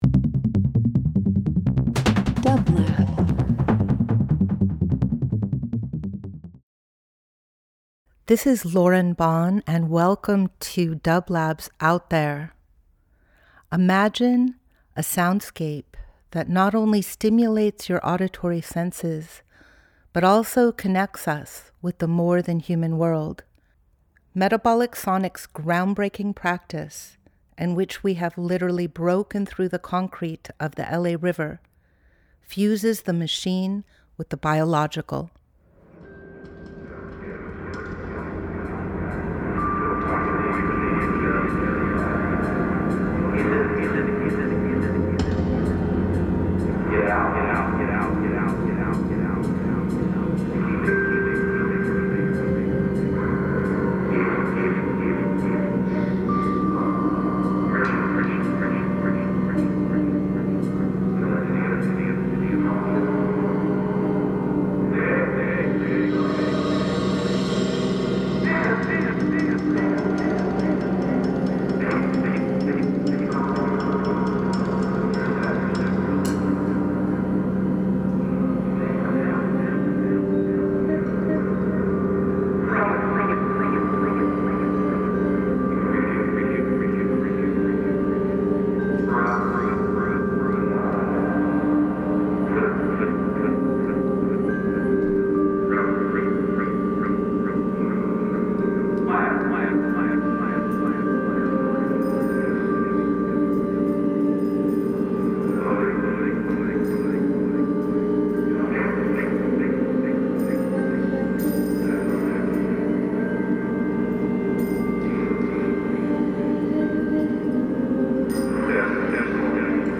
Each week we present field recordings that will transport you through the power of sound.
Out There ~ a field recording program
Metabolic Sonics specializes in exploring the captivating sound waves and vibrations of the web of life.
Each week we will bring field recordings of Metabolic’s current project Bending the River, and archival material from past ventures. Bending the River is an adaptive reuse of the LA River infrastructure that reimagines the relationship between Los Angeles and the river that brought it into existence.